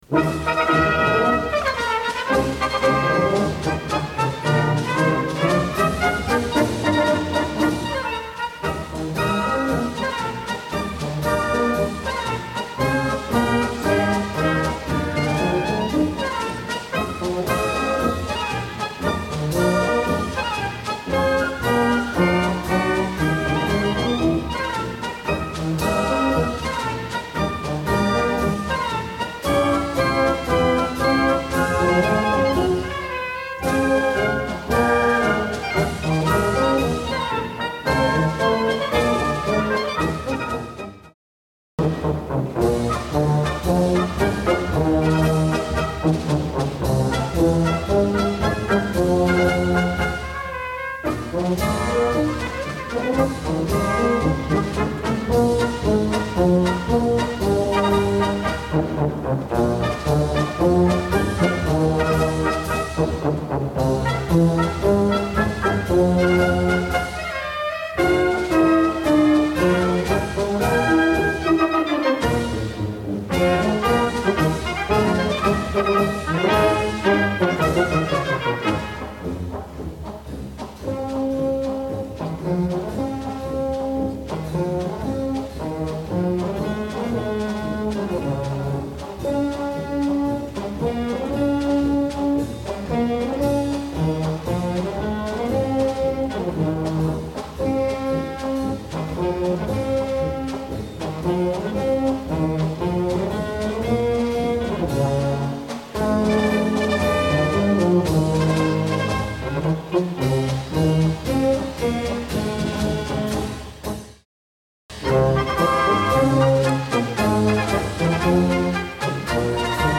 Harmonie